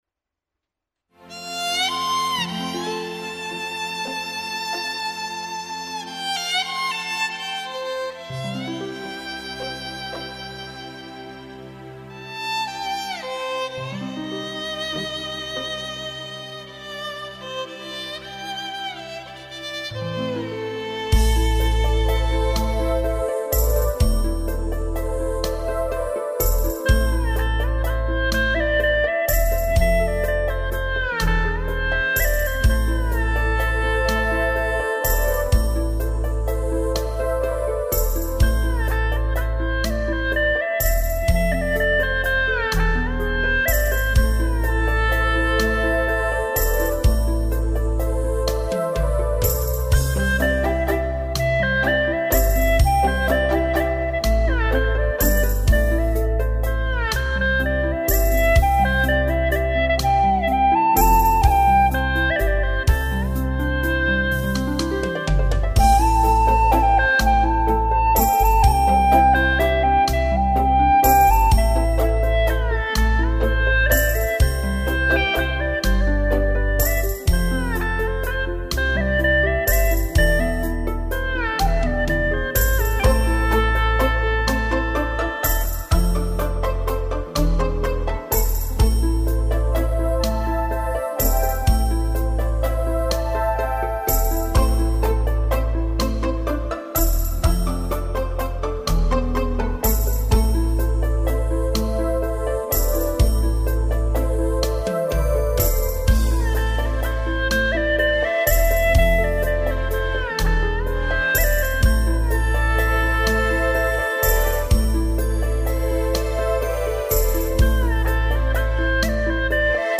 调式 : C 曲类 : 民族
以其悠扬的曲调、娓娓动听的旋律，给人心旷神怡的感觉